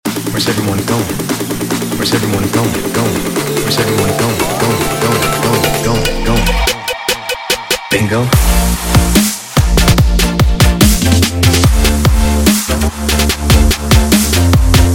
This scene was just begging to be turned into an EDM drop.